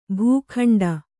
♪ bhū khaṇḍa